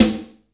• Bright Snare Drum Sample A Key 352.wav
Royality free acoustic snare sample tuned to the A note. Loudest frequency: 3353Hz
bright-snare-drum-sample-a-key-352-qnz.wav